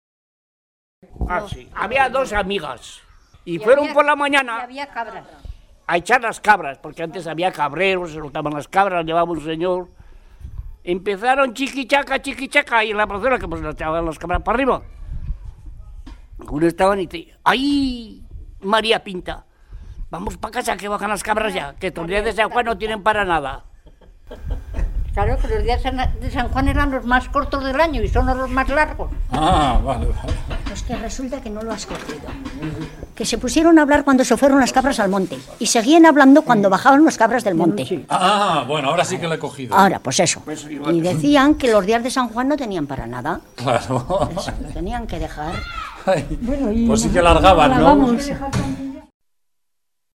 Clasificación: Cuentos